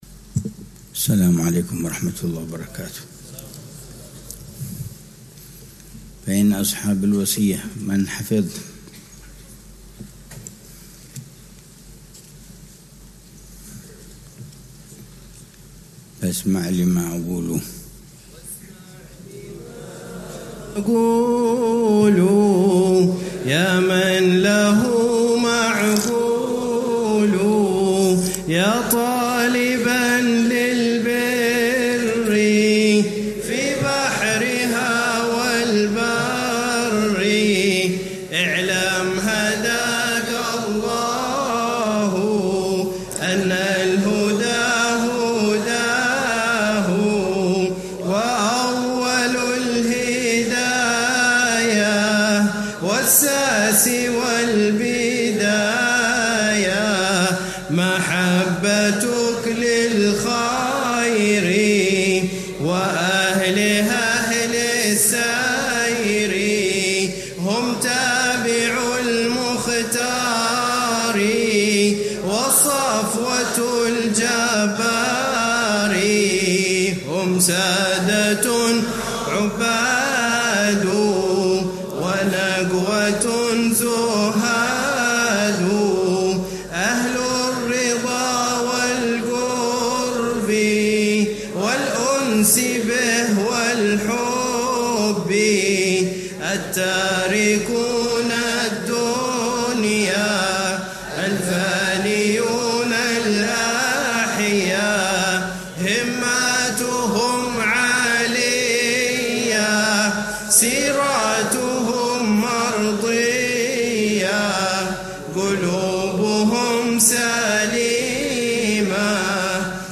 شرح الحبيب عمر بن حفيظ على منظومة «هدية الصديق للأخ والرفيق» للحبيب عبد الله بن حسين بن طاهر. الدرس الثامن (19 محرم 1447هـ)